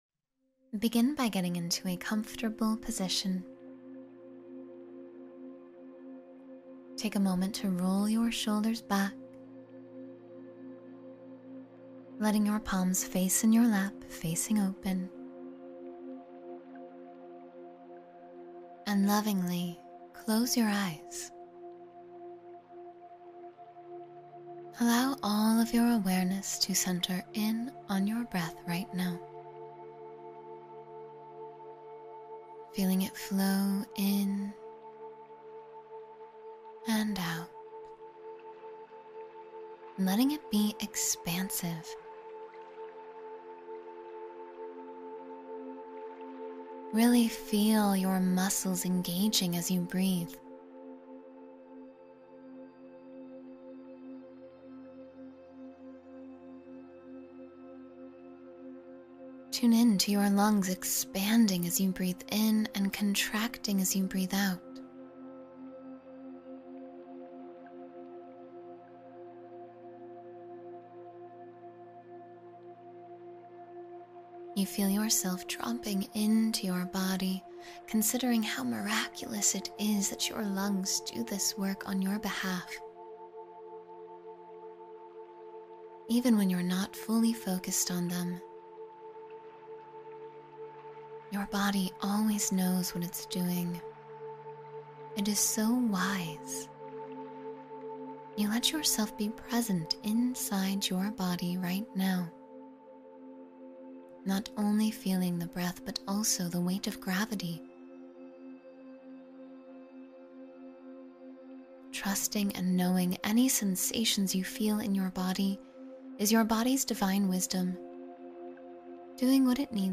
Feel the Power of Intention Every Morning — Meditation for Manifestation